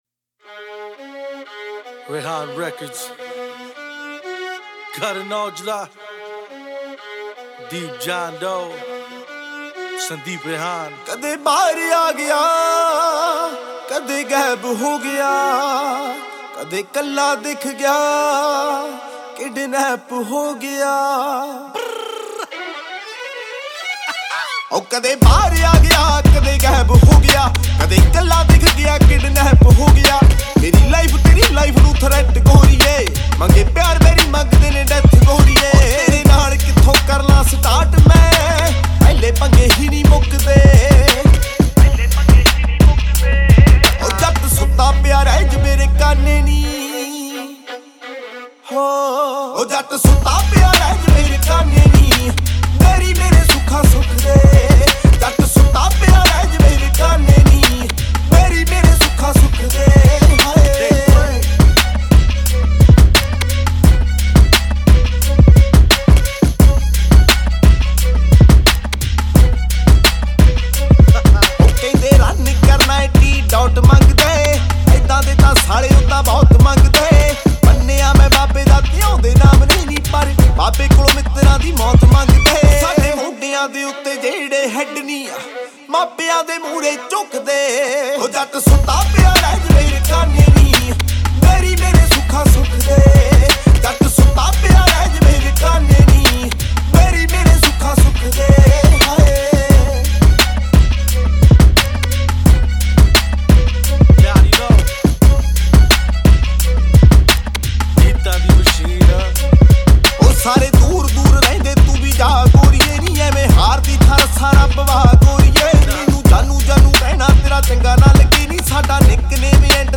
2020 Punjabi Mp3 Songs
Punjabi Bhangra MP3 Songs